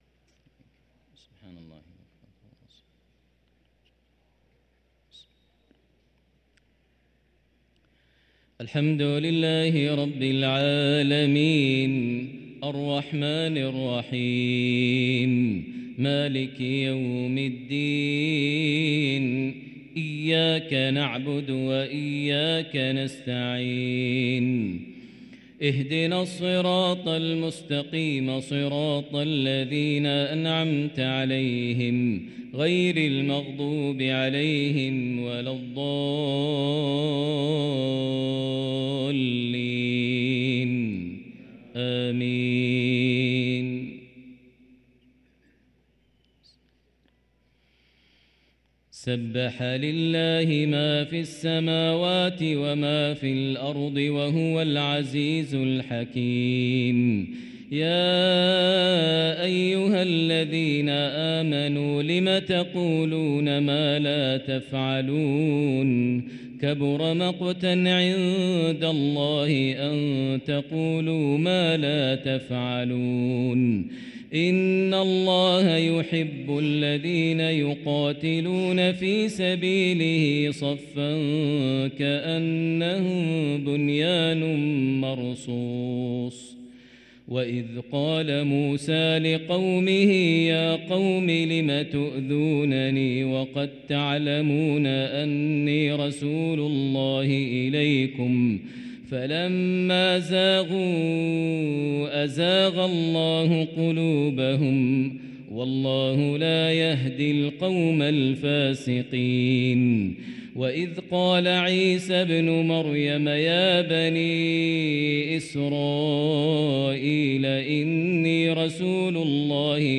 صلاة العشاء للقارئ ماهر المعيقلي 18 شعبان 1444 هـ
تِلَاوَات الْحَرَمَيْن .